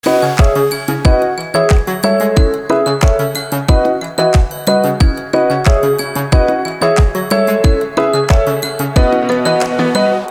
громкие
Electronic
электронная музыка
без слов
клавишные